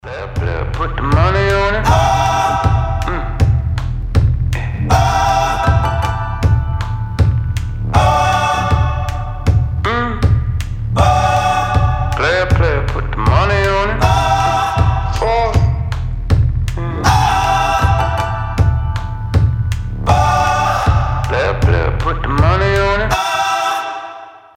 • Качество: 320, Stereo
атмосферные
пианино
RnB
alternative
эпичные
Фанк